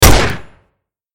Download Gunshot sound effect for free.
Gunshot